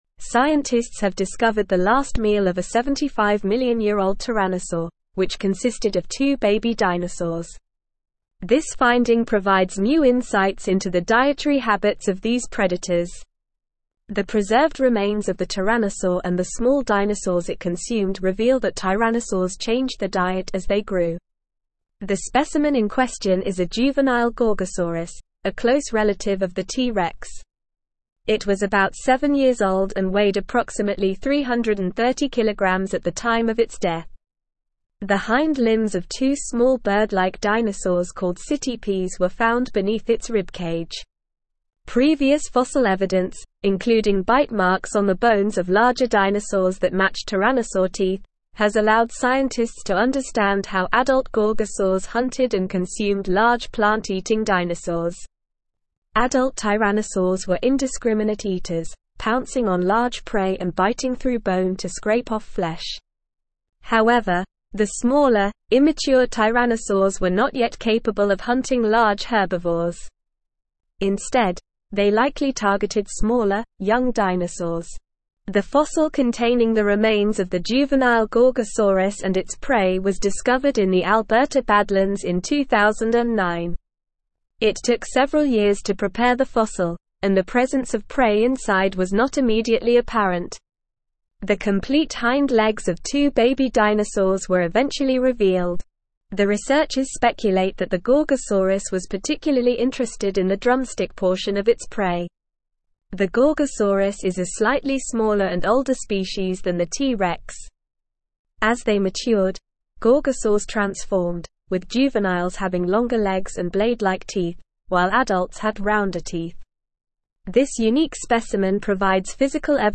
Normal
English-Newsroom-Advanced-NORMAL-Reading-Ancient-Tyrannosaurs-Last-Meal-Two-Baby-Dinosaurs.mp3